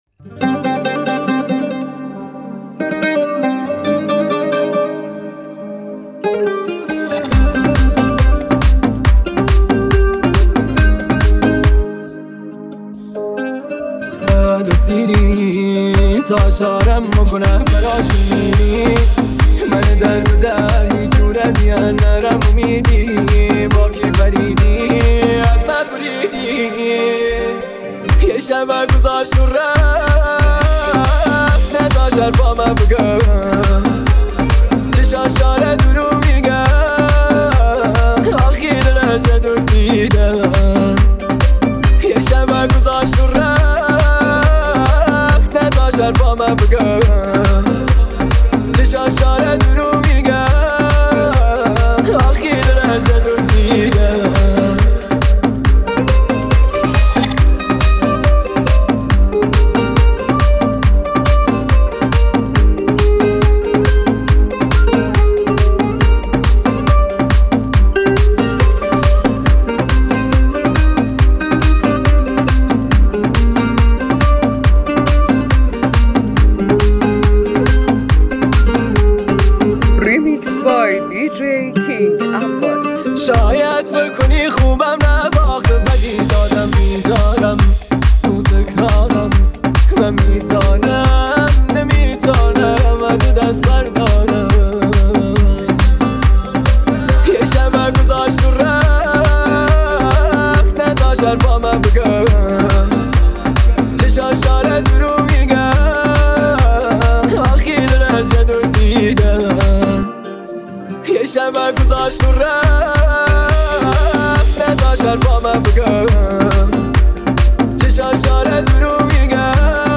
Download Old Remix BY